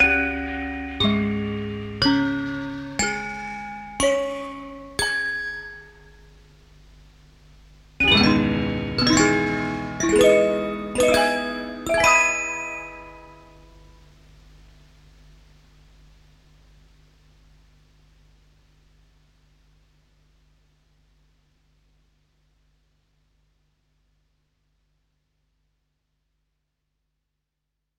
它具有有机的声音，带有许多小的不完美之处。